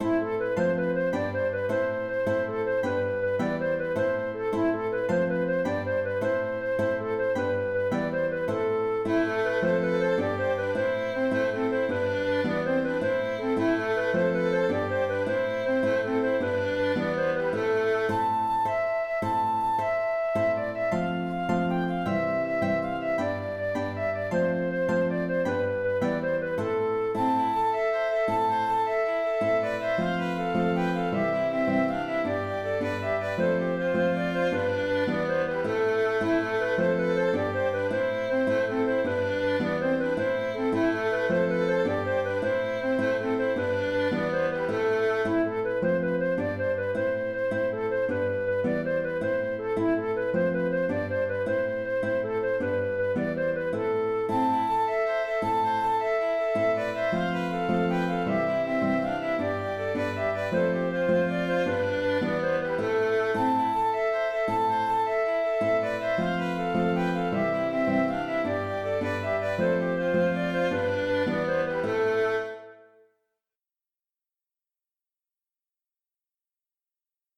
Tarentelle (Tarentelle) - Musique folk
Dans le fichier audio, le tempo est relativement tranquille.